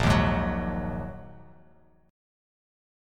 A#7sus4#5 chord